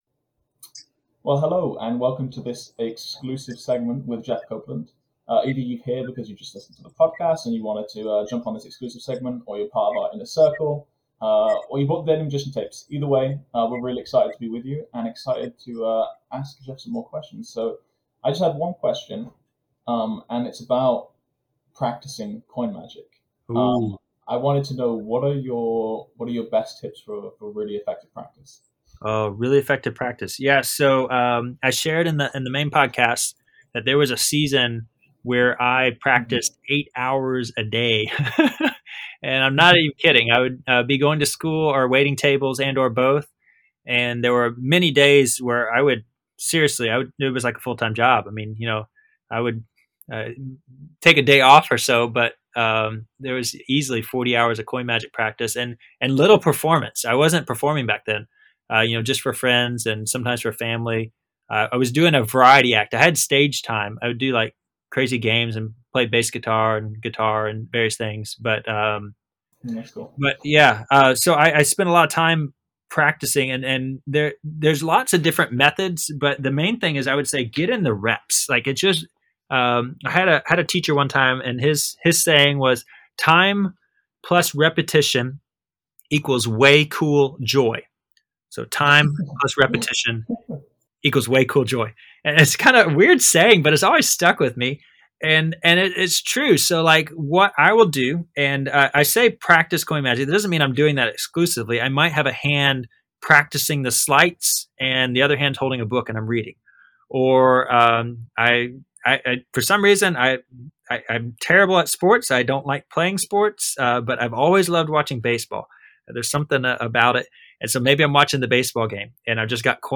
(apologies for my mic quality, for some reason the software decided to record through my webcam…)